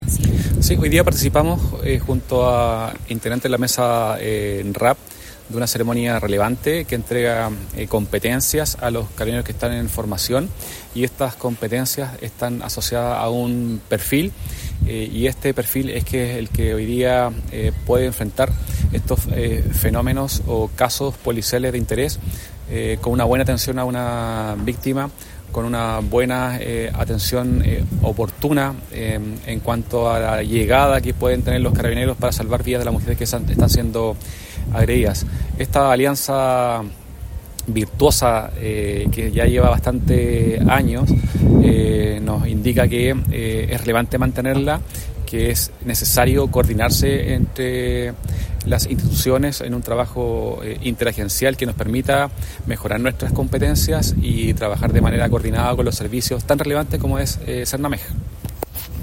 Por su parte, el Coronel Francisco Aravena, Prefecto de Coquimbo valoró la instancia como un paso más en el fortalecimiento la institución y su compromiso para contribuir a una sociedad más justa, equitativa y segura.
Coronel-Francisco-Aravena-Prefecto-de-Coquimbo.mp3